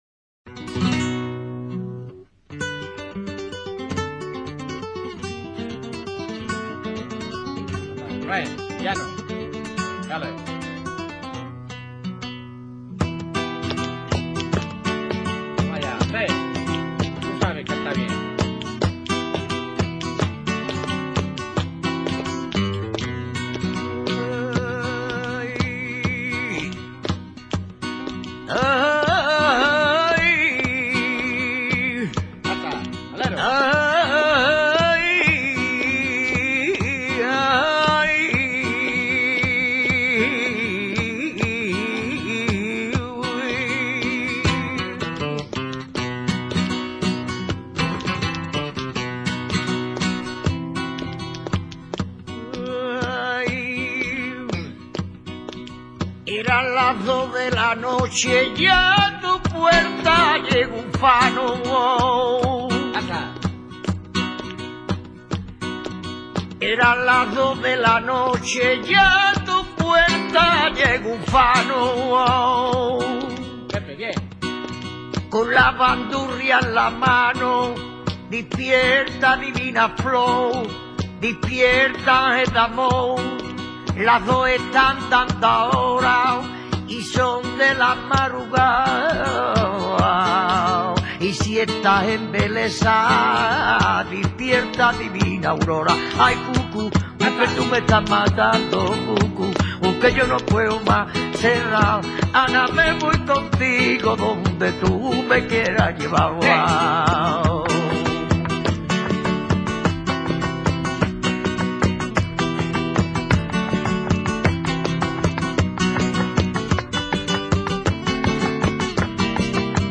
MILONGA. f. [De la lengua blinda; designa el sitio donde se baila y la reuni�n en s�.
milonga.mp3